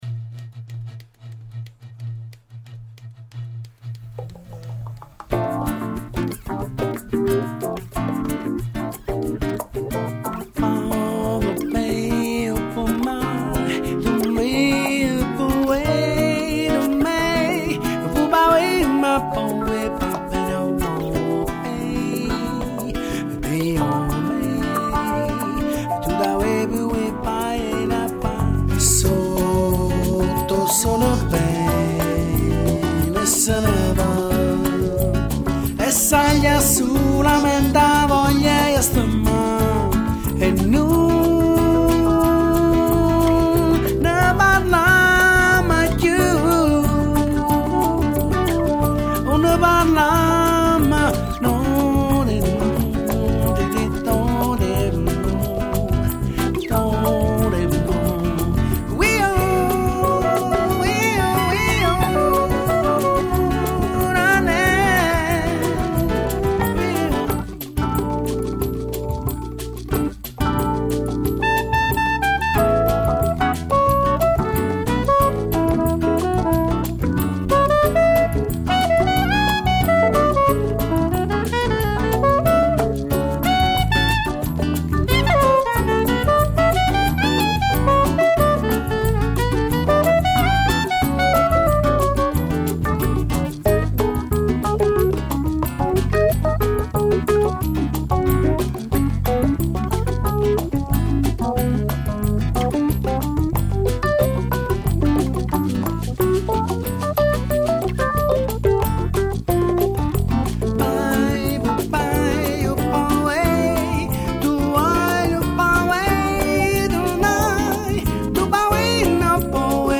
suona una chiave inglese